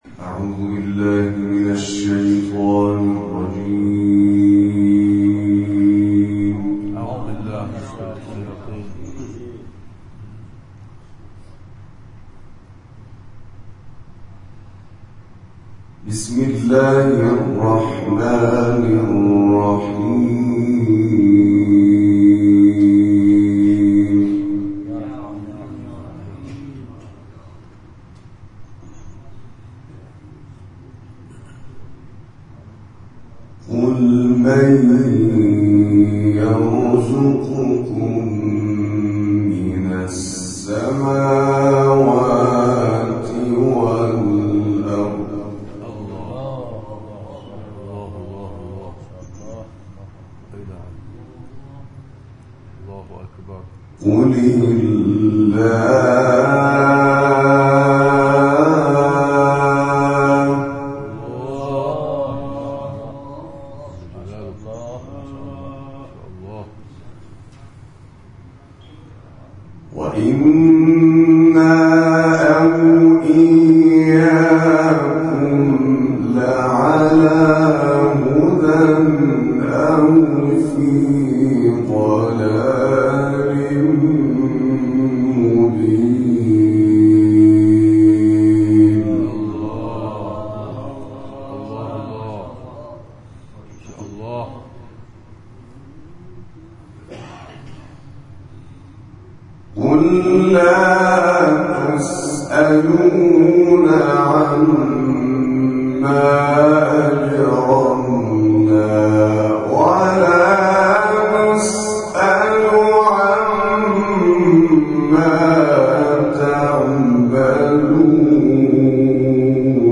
دقایقی به تلاوت آیاتی از ادامه درس جلسه پرداختند.
تلاوت قرآن